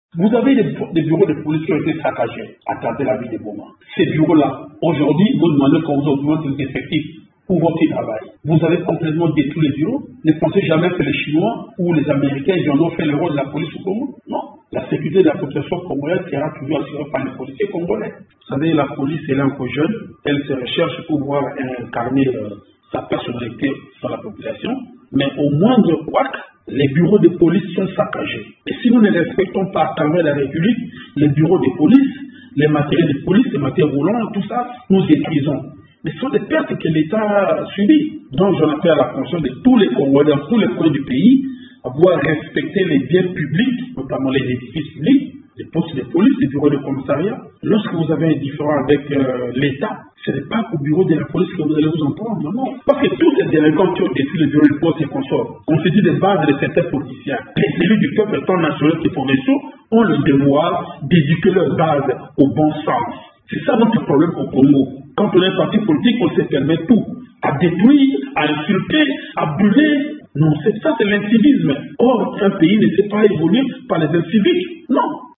Dans une interview exclusive accordée à Radio Okapi, il souligne que les infrastructures de la police font partie des biens publics que la population doit respecter.
Le commissaire divisionnaire principal Alongabony, qui appelle la population à cultiver le patriotisme et le civisme, demande aux leaders d'opinion notamment les élus du peuple à accompagner l'État congolais à l'éducation de la masse :